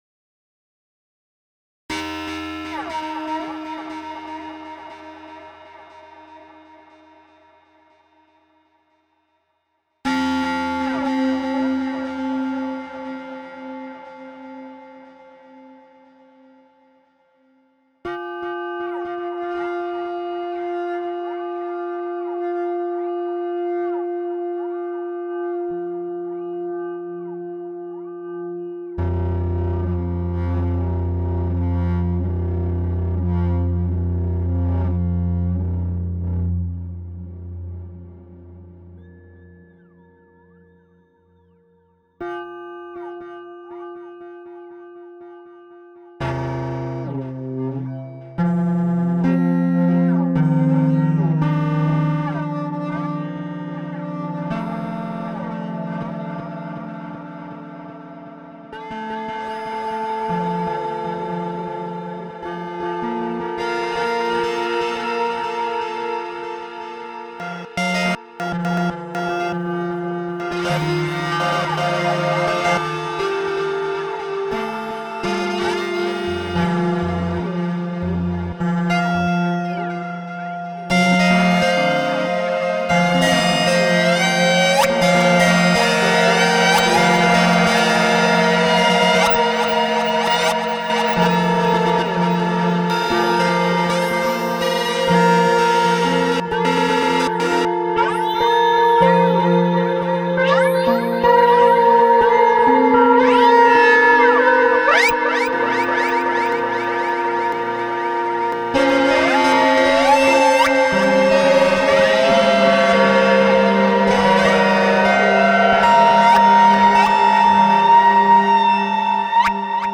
for experimental electronics